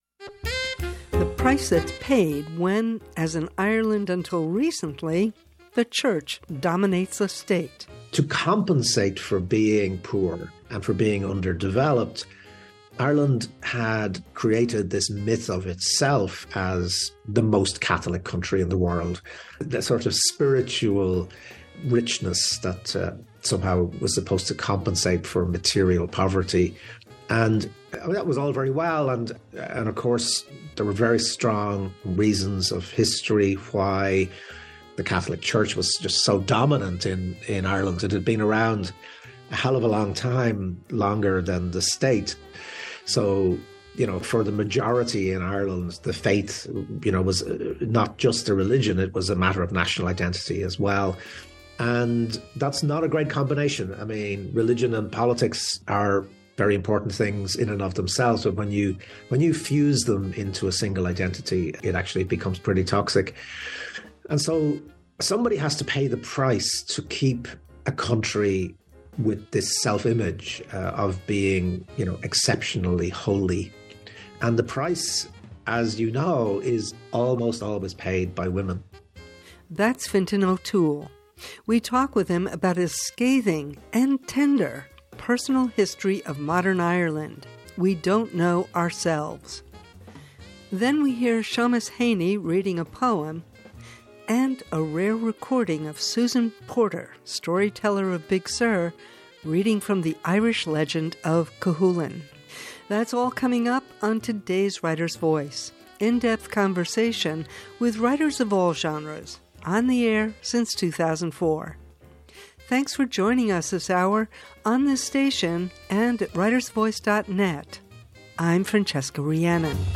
Writers Voice— in depth conversation with writers of all genres, on the air since 2004.
author interview